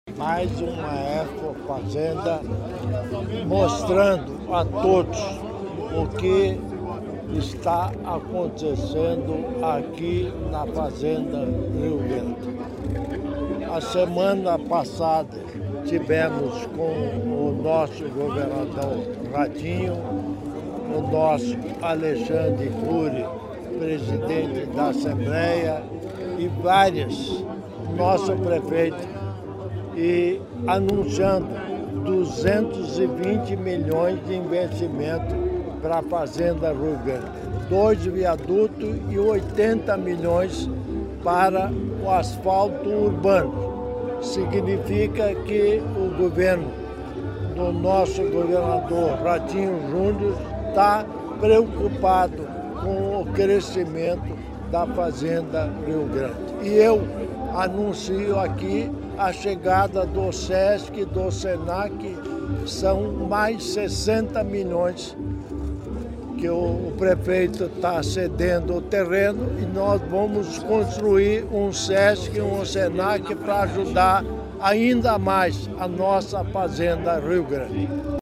Sonora do governador em exercício Darci Piana durante a ExpoFazenda 2025